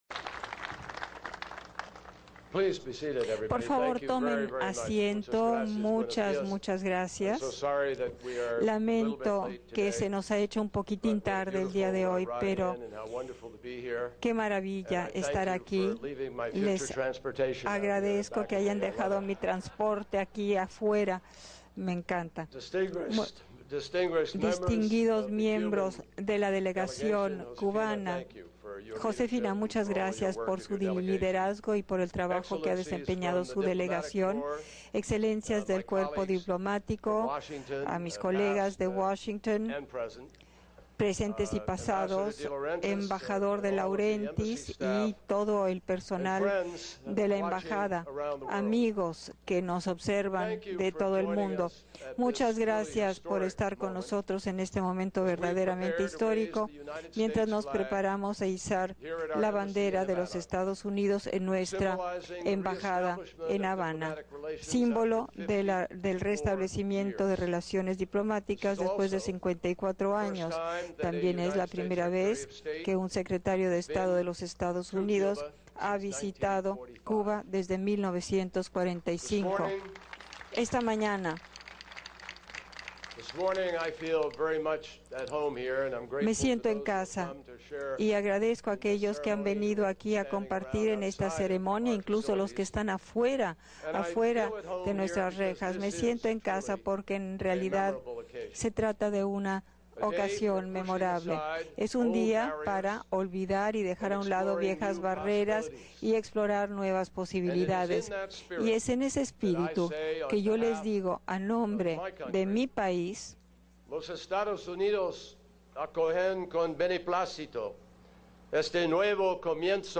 Discurso de John Kerry en la embajada de Estados Unidos en La Habana
Palabras del Secretario de Estado, John Kerry, en la ceremonia de izaje de la bandera estadounidense en la embajada de Estados Unidos en La Habana.